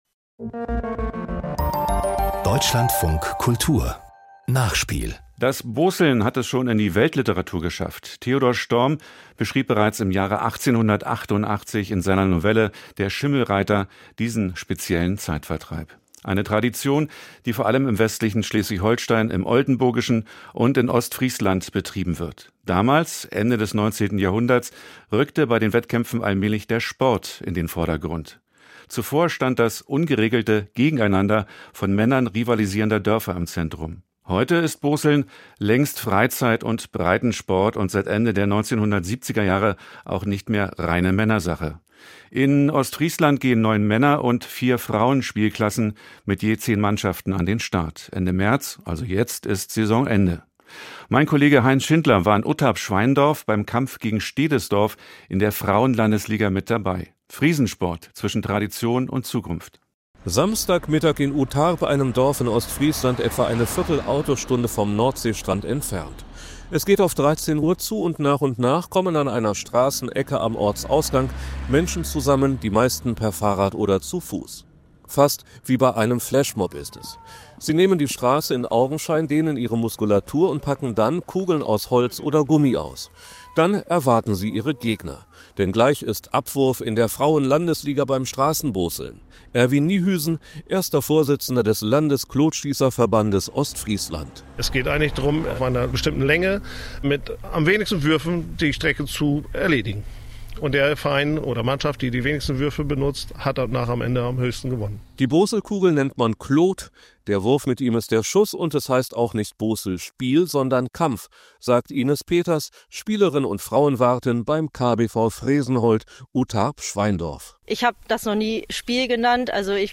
Das Interview zum 16. Spieltag der Landesliga Frauen I wurde am 1. März 2025 vom Radiosender Deutschlandfunk Kultur aufgezeichnet und gibt spannende Einblicke zum Boßelsport und zum Wettkampf zwischen Utarp-Schweindorf und Stedesdorf.